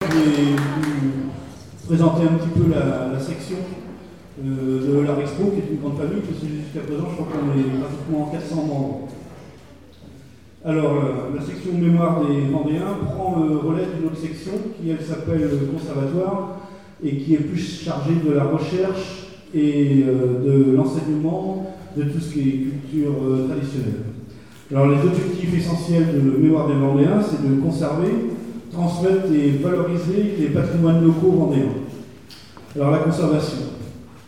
Présentation de la soirée de lancement d'une édition musicale
Présentation lors de la sortie de la cassette audio
Catégorie Témoignage